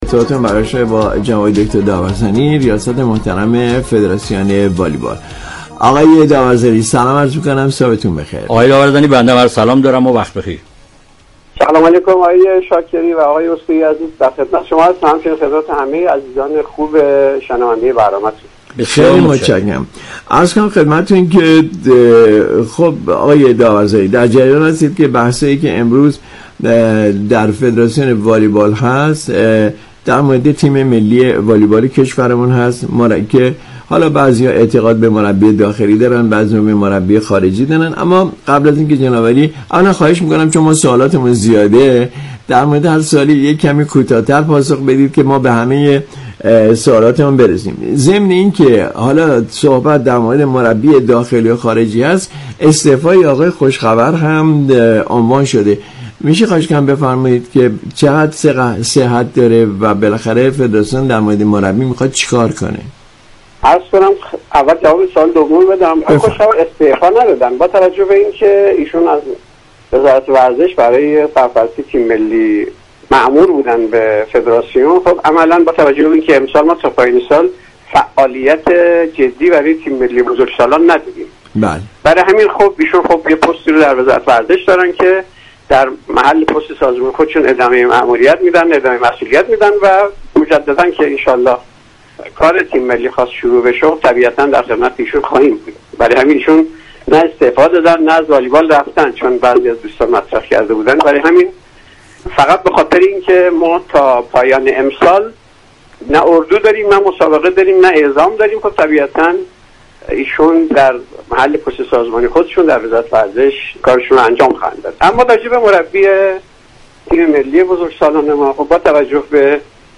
در گفتگو با برنامه